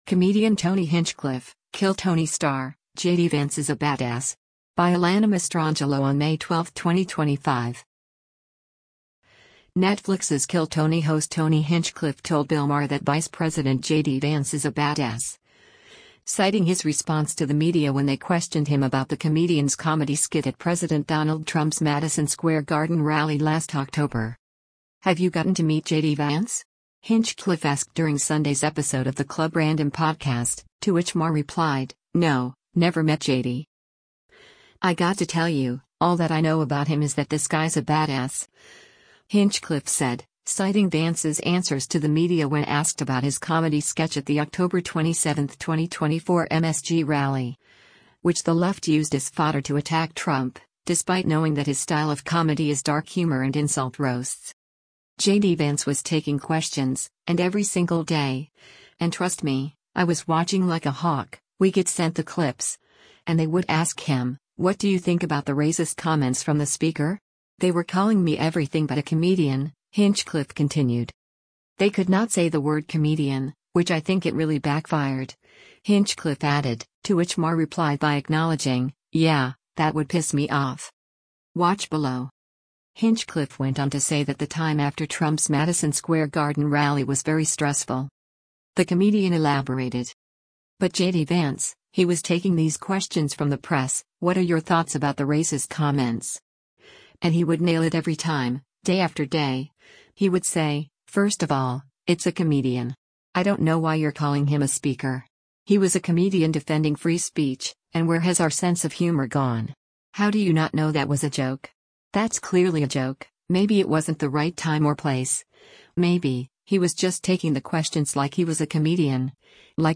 “Have you gotten to meet JD Vance?” Hinchcliffe asked during Sunday’s episode of the Club Random Podcast, to which Maher replied, “No, never met JD.”
Elsewhere in the interview, Hinchcliffe noted that “acceptance” and “invitational” energy of “let’s break bread” is “somehow becoming slightly a more quality of the right,” adding that them calling everyone “racist” is “where everybody really fucked up.”